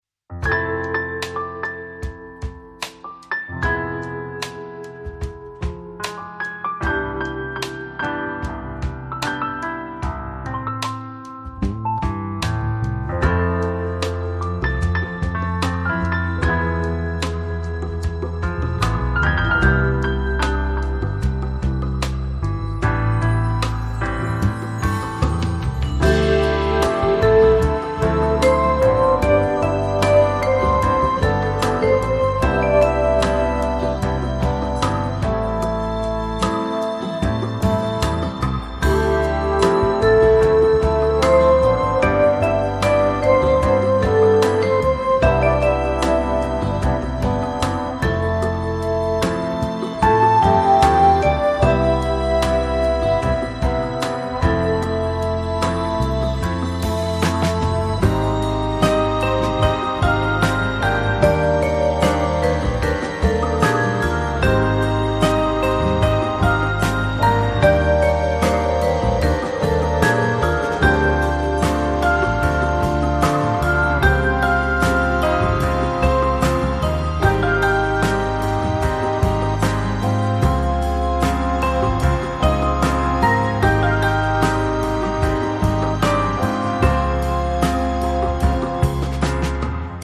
piano and acoustic guitar
drums and percussion